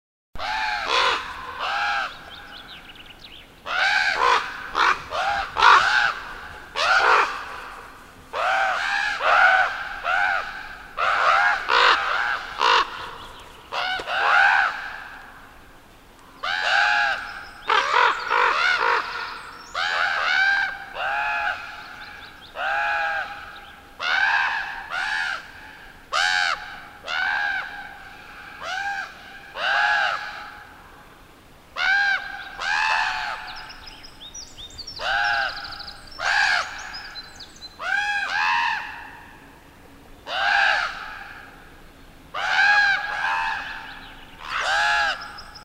Korppi (Corvus corax) Northern Raven -